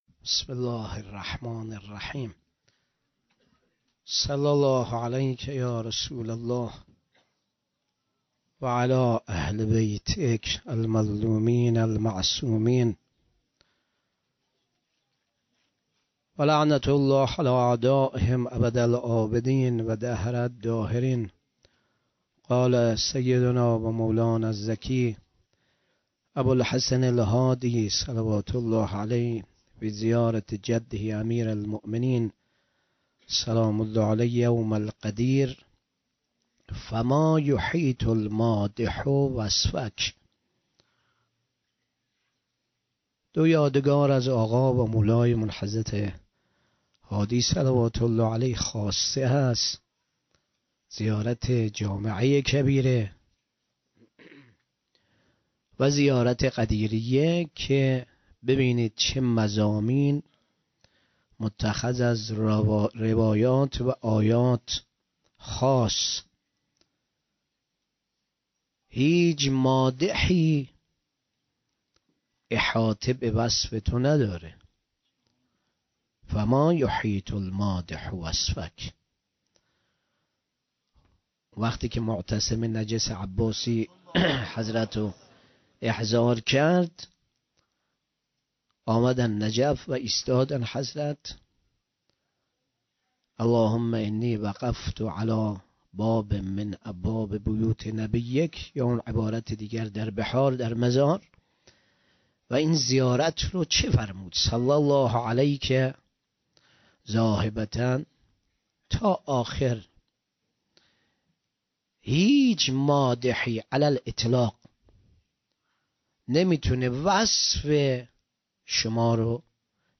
شب بیست و سوم رمضان 96 - غمخانه بی بی شهربانو - سخنرانی